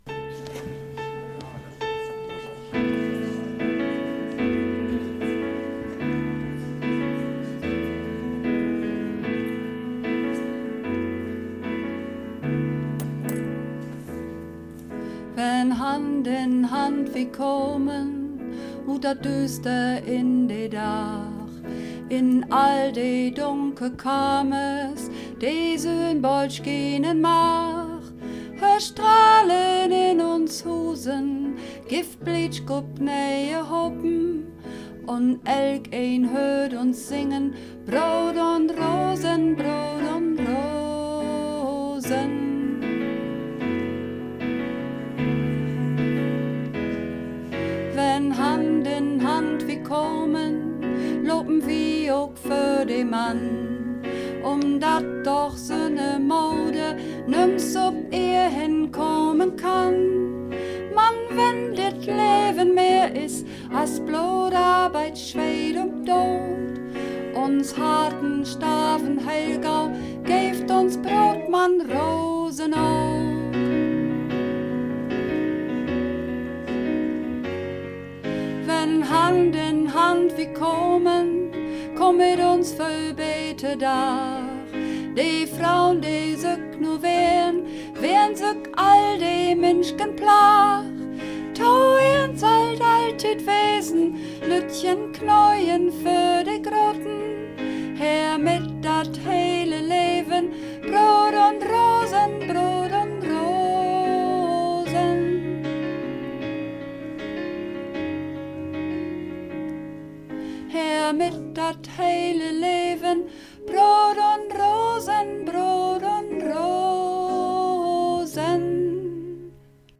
Übungsaufnahmen - Brood un Rosen
Brood_un_Rosen__2_Sopran.mp3